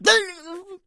PigHit 05.wav